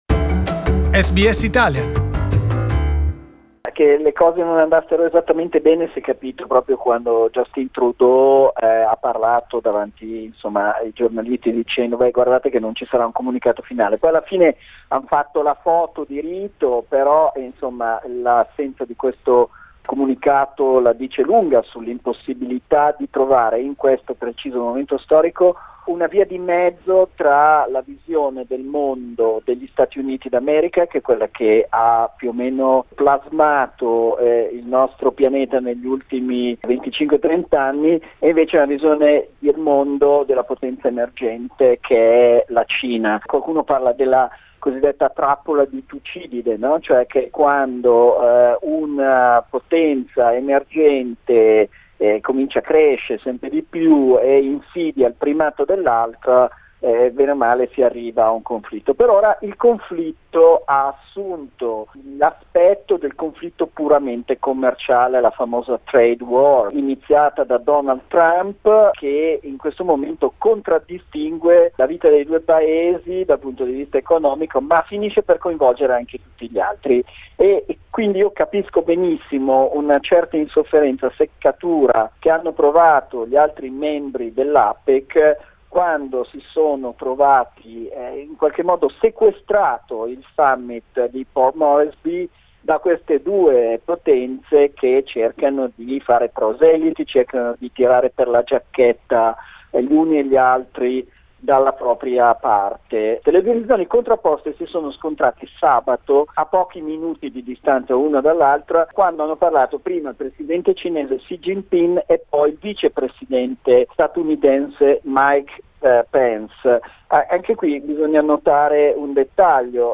But for the first time the 21 member states did not reach an agreement on a final communiqué, mainly because of the profound differences between the US and China. Our Beijing correspondent